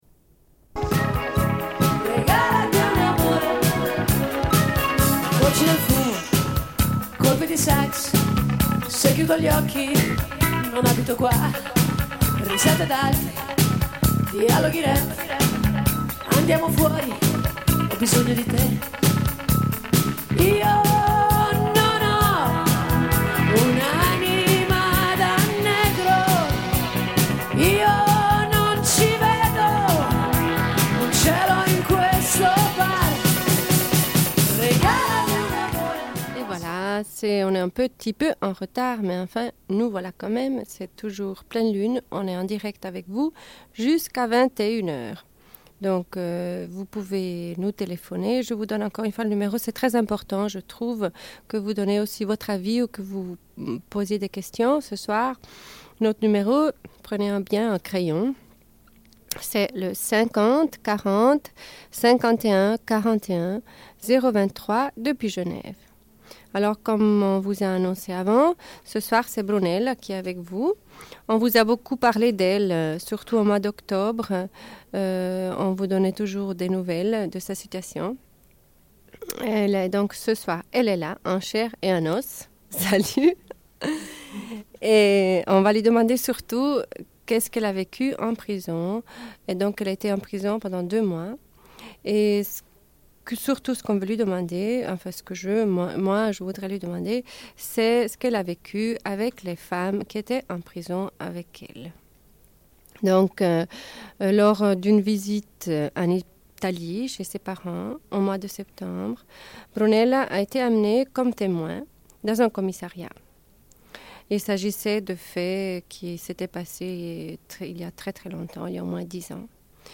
Une cassette audio, face A31:33
Radio Enregistrement sonore